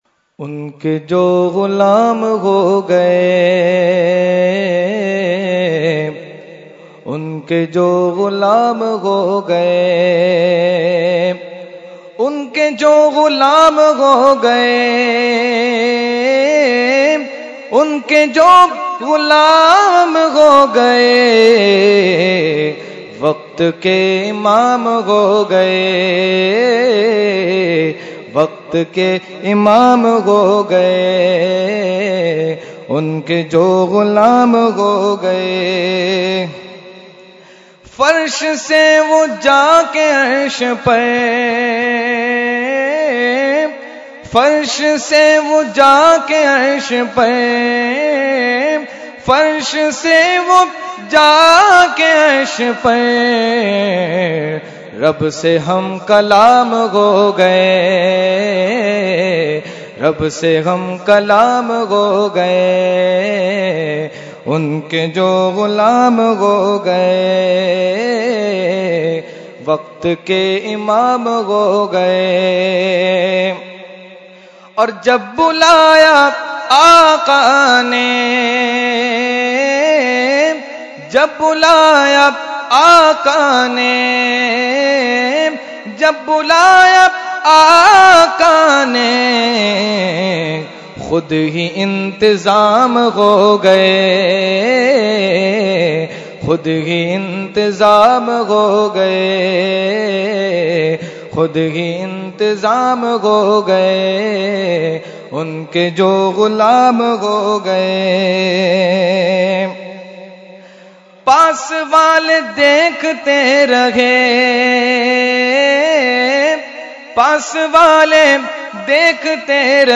Category : Naat | Language : UrduEvent : 11veen Shareef 2018